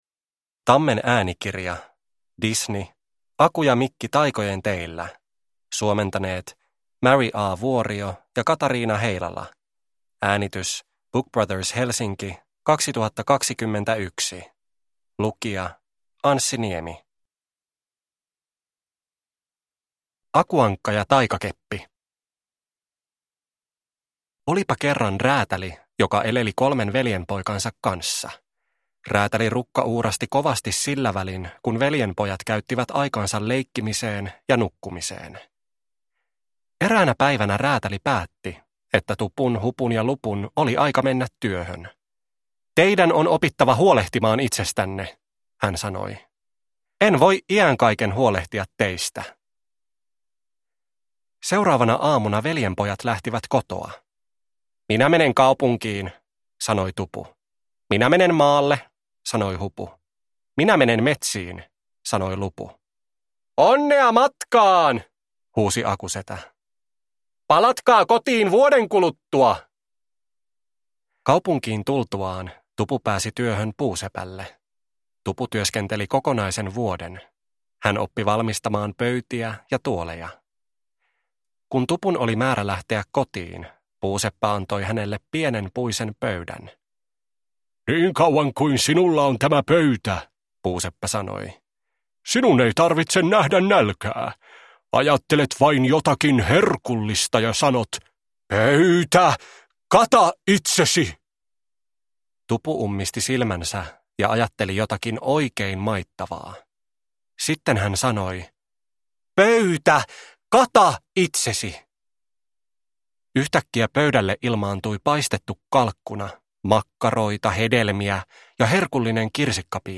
Aku ja Mikki taikojen teillä – Ljudbok